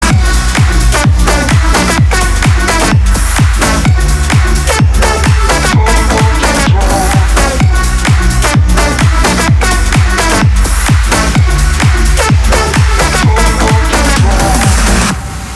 • Качество: 320, Stereo
ритмичные
громкие
EDM
future house
progressive house
Жанр: Танцевальная/электронная музыка